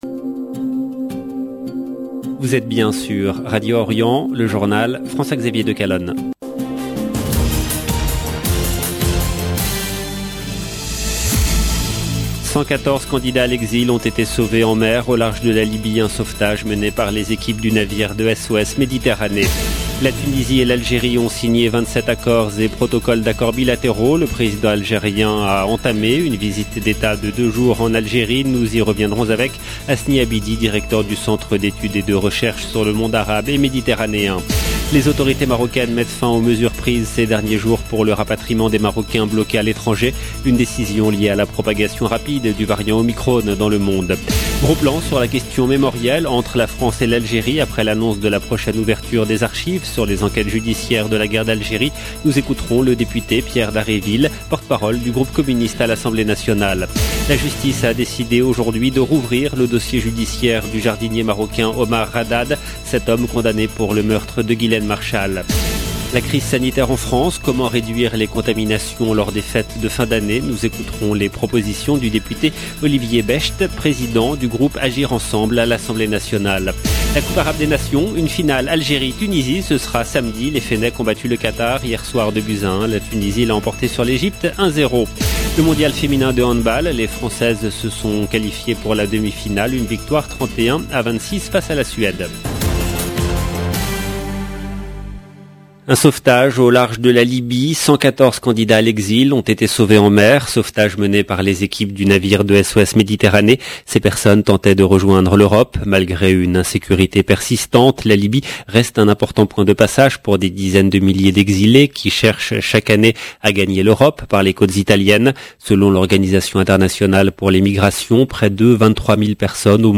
LE JOURNAL DU SOIR EN LANGUE FRANCAISE DU 16/12/21 LB JOURNAL EN LANGUE FRANÇAISE